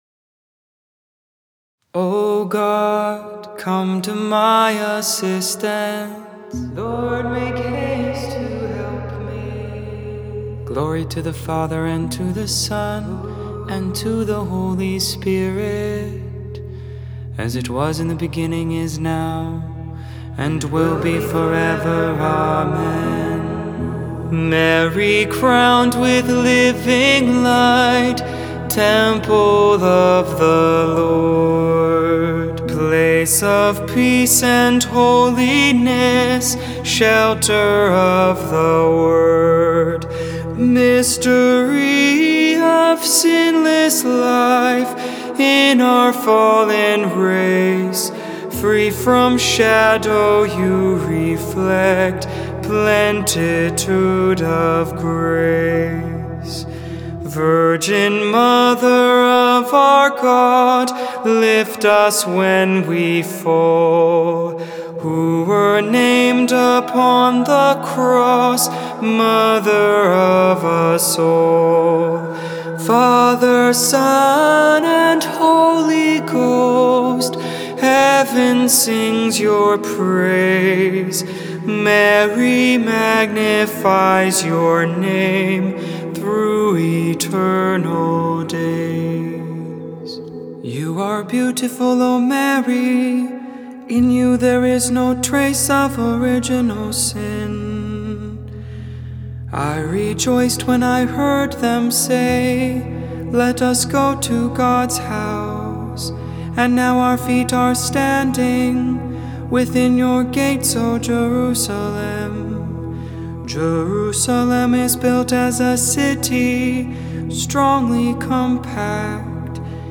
Hymn - Mary, crowned with living light Psalm 122 Psalm 127 Ephesians 1:3-10 Romans 5:20-21 Canticle of Mary (Luke 1:46-55)